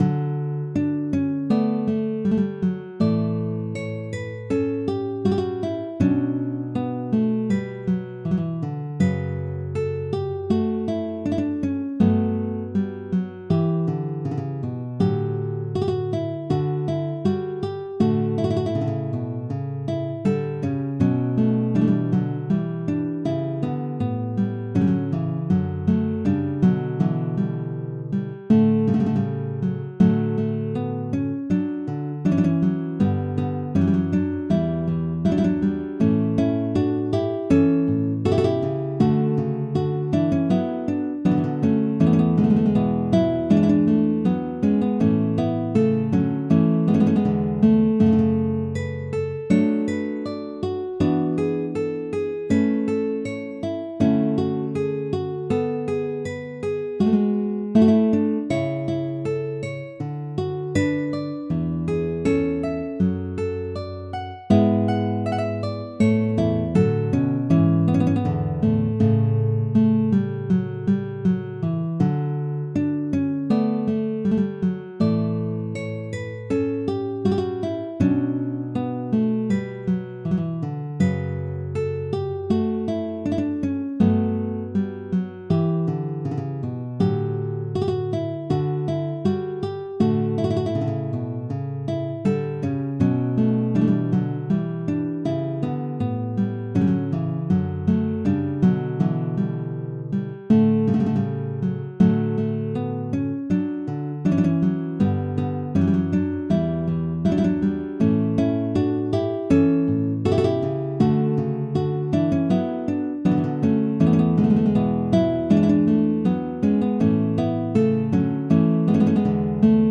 練習中の曲目